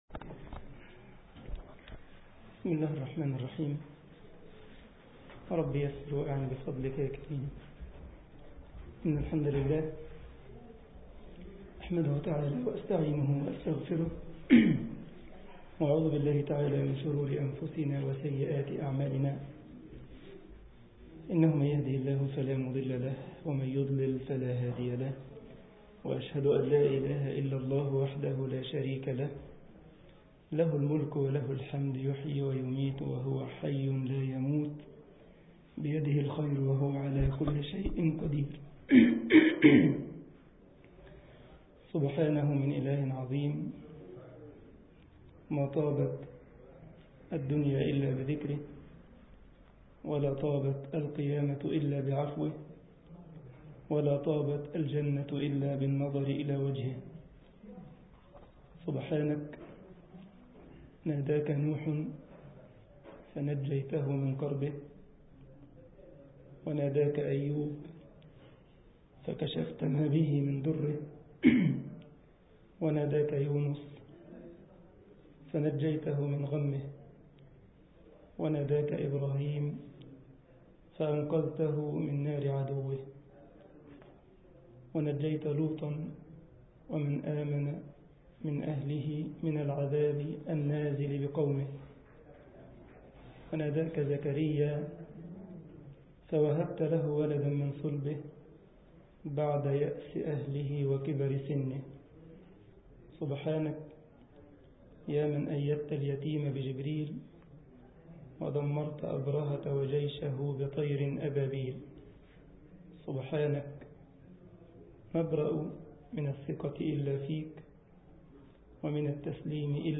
مسجد الجمعية الإسلامية بكايزرسلاوترن ـ ألمانيا درس